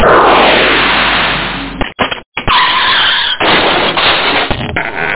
Amiga 8-bit Sampled Voice
1 channel
sample01.swoosh.mp3